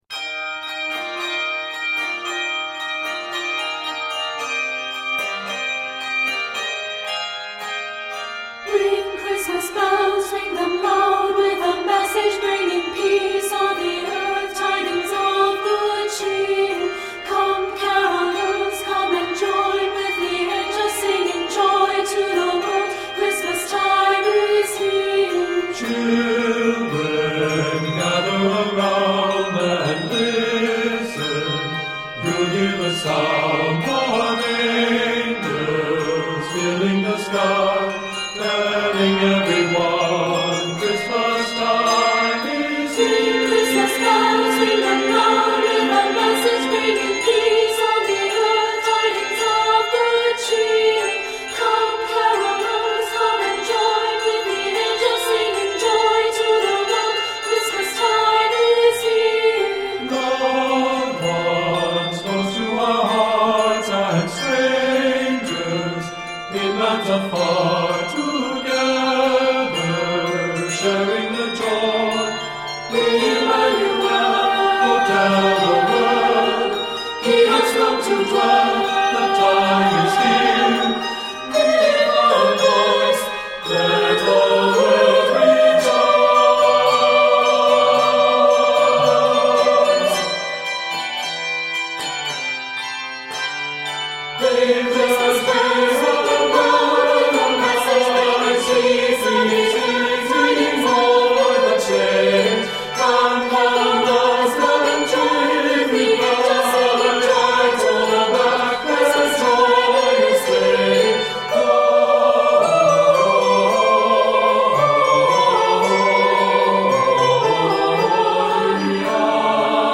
this celebratory anthem is teeming with energy and joy.